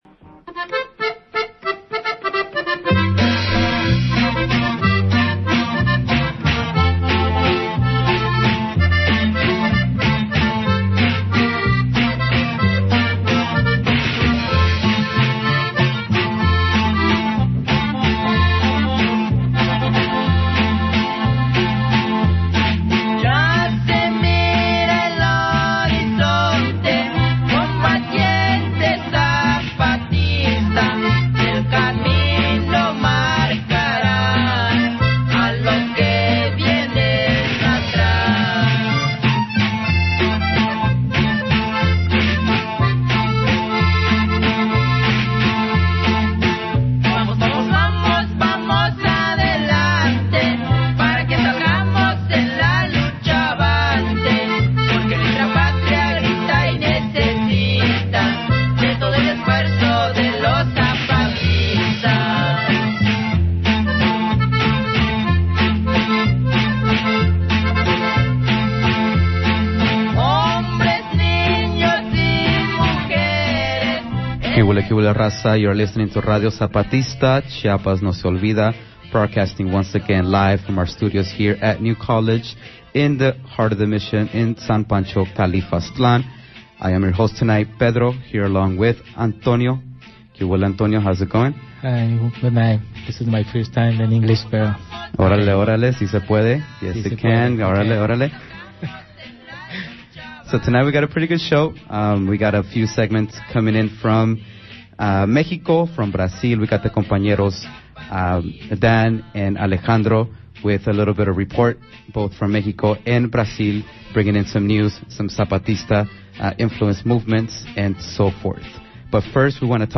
Our live radio show is open to the public, so if you're in the area come on in, bring the gang, make some noise, join the Otra...